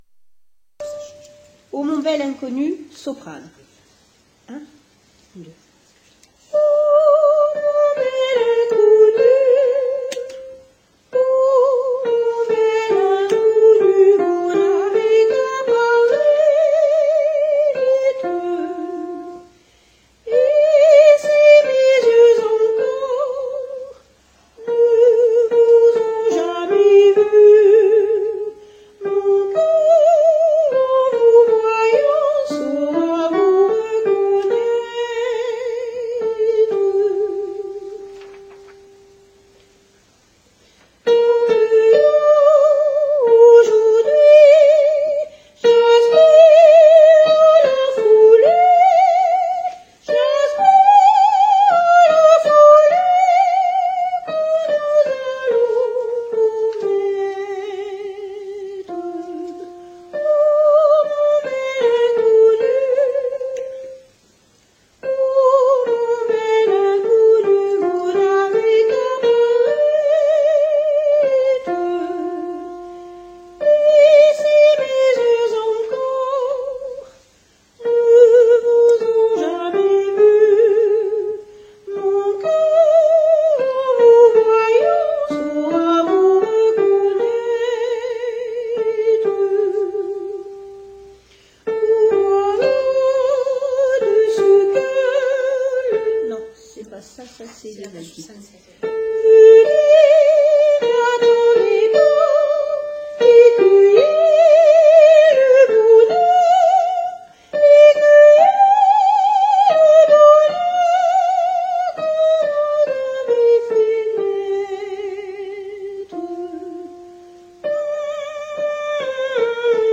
soprani